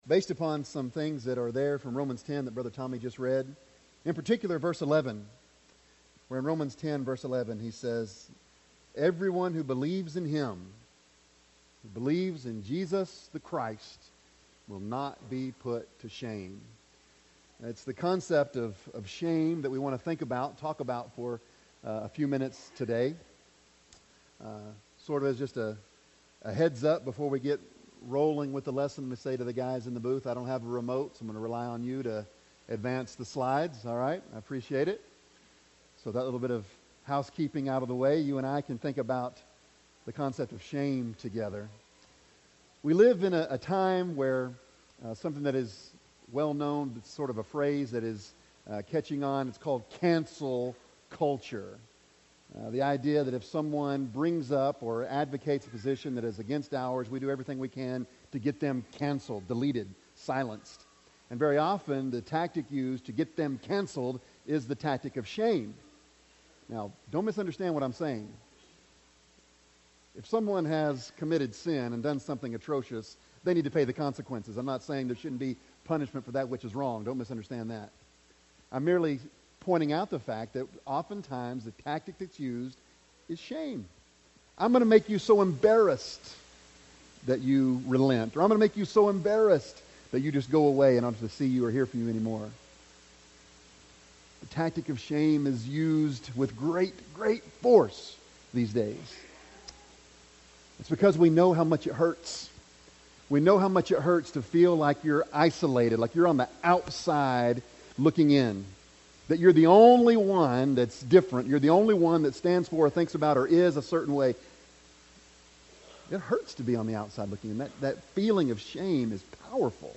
Romans 10:11 Service Type: Sunday Morning Bible Text